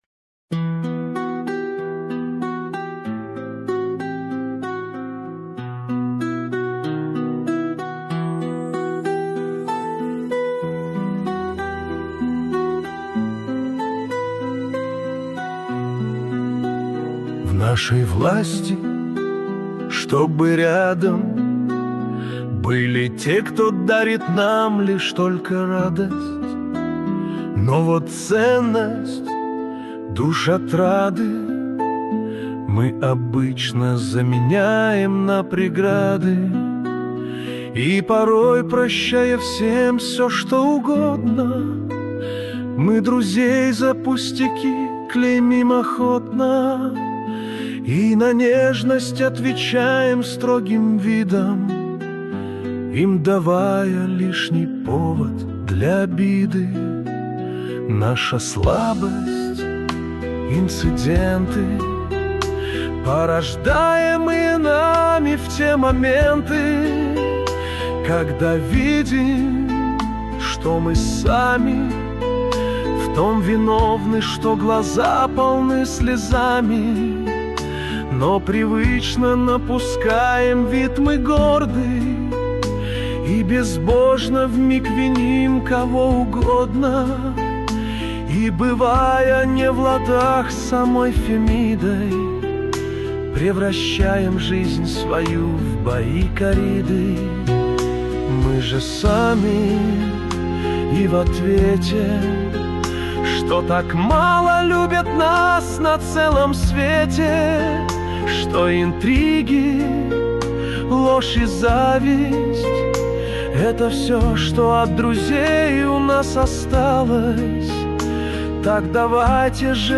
Для Медитаций